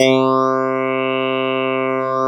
Index of /90_sSampleCDs/USB Soundscan vol.09 - Keyboards Old School [AKAI] 1CD/Partition B/04-CLAVINET3
CLAVI3  C3.wav